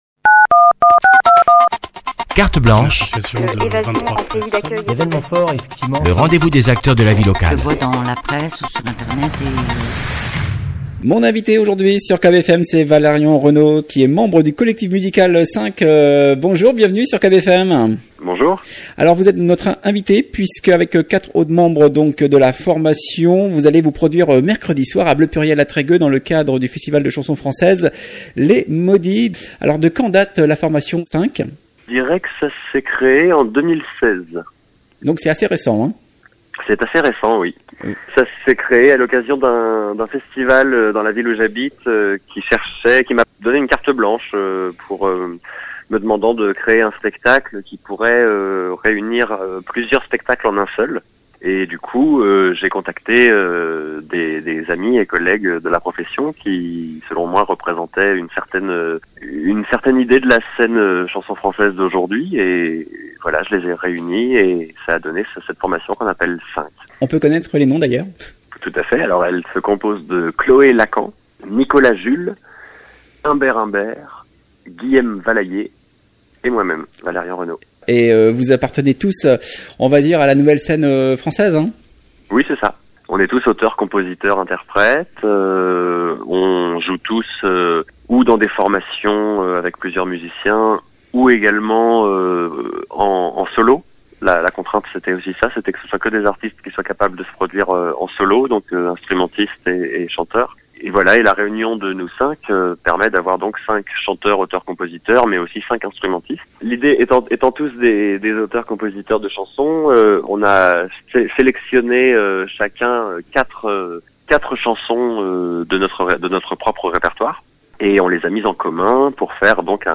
Invité de la rédaction ce lundi